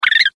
Здесь вы можете слушать и скачивать его характерные писки, свисты и другие звуки, которые он издает в природе.
Звук с писком суслика